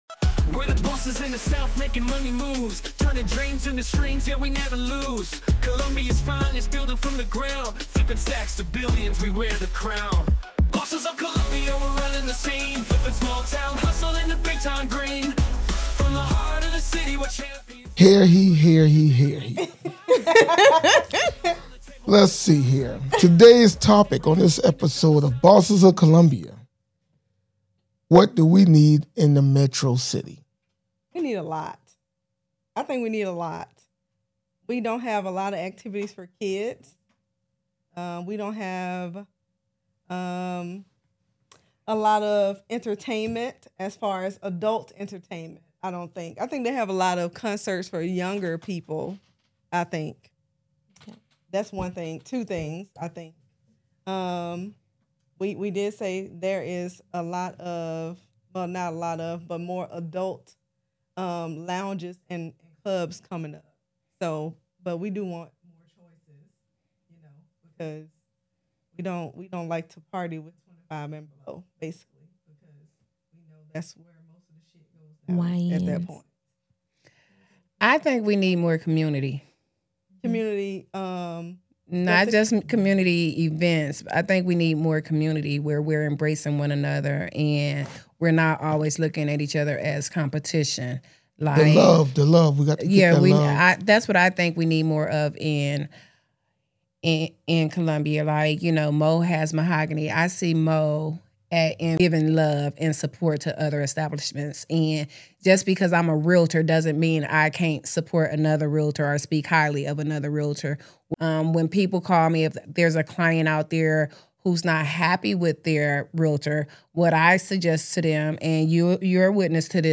Welcome to "Bosses of Columbia," the podcast where a diverse group of entrepreneurs from real estate, entertainment, and various other industries come together to share their experiences of collaboration and success. Join us as we dive into the inspiring stories and innovative strategies of successful leaders who are shaping the landscape of Columbia.